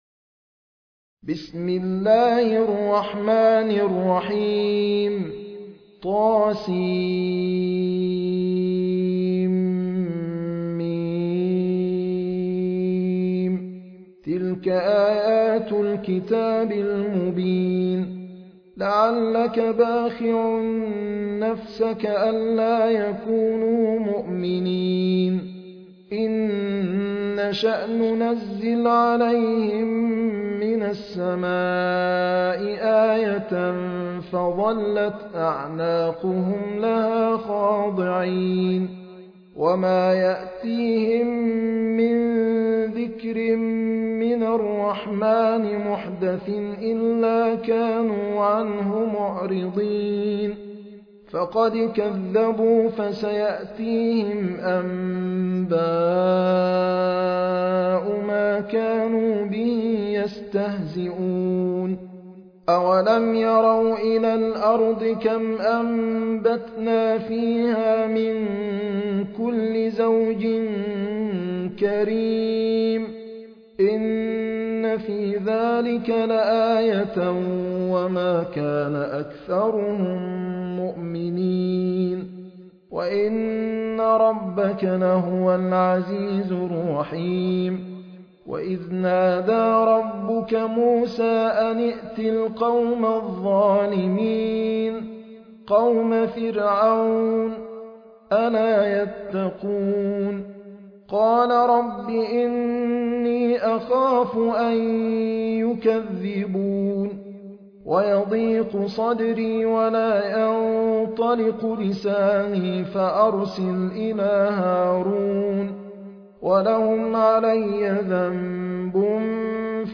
High Quality Quranic recitations and Islamic Lectures from selected scholars
المصحف المرتل - حفص عن عاصم - Ash-Shuara ( The Poets )